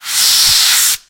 extinguisher.3.ogg